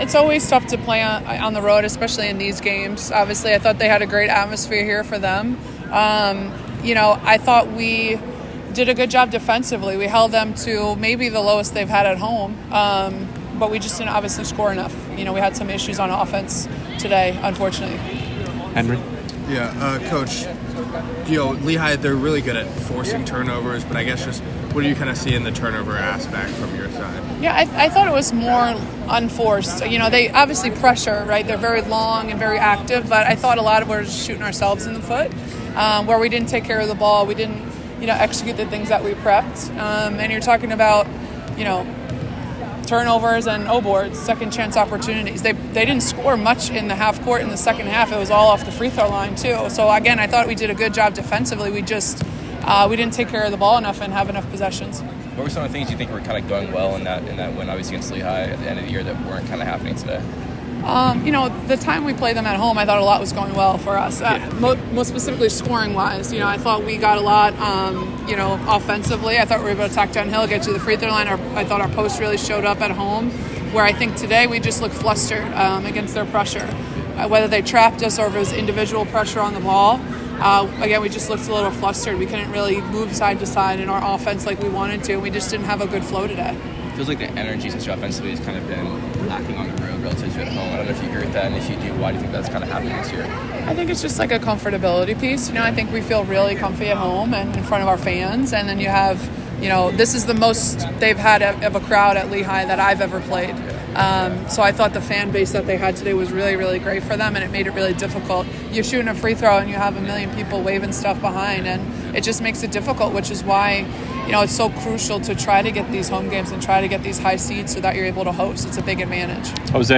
WBB_PL_QF_Postgame.mp3